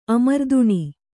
♪ amarduṇi